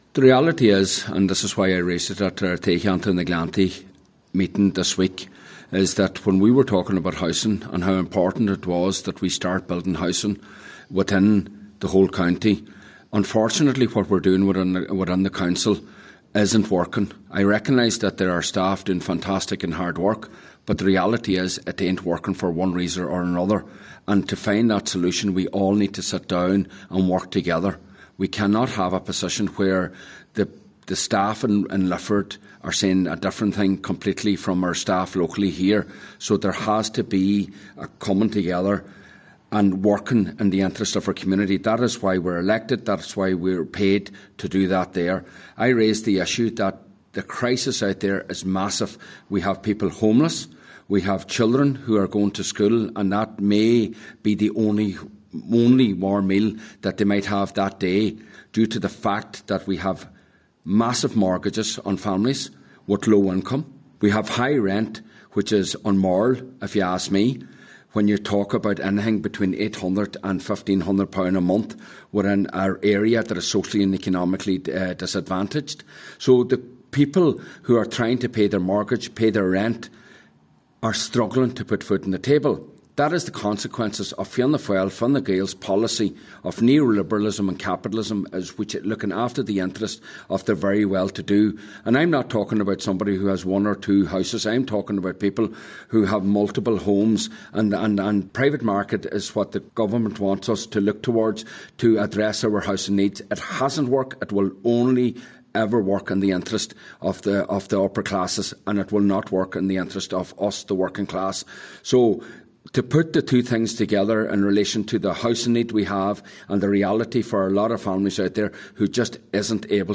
Councillor MacGiolla Easbuig says a more joint up approach is needed from Donegal County Council also: